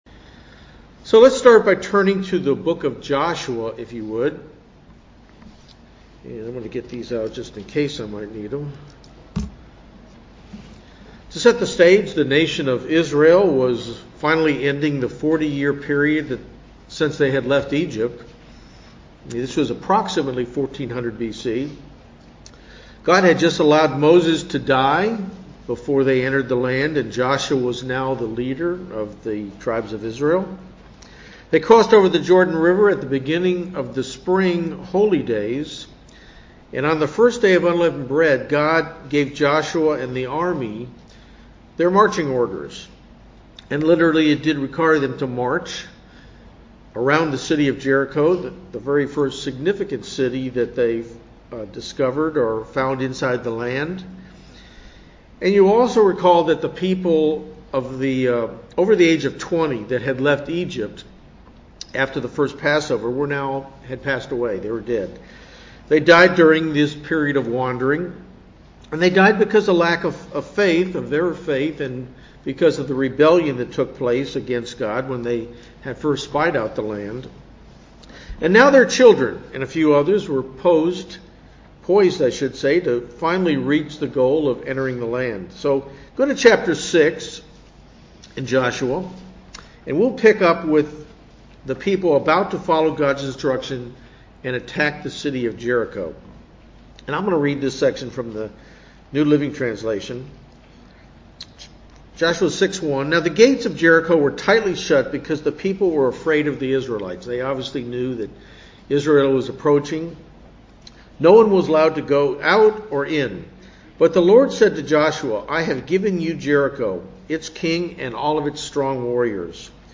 Webcast Sermons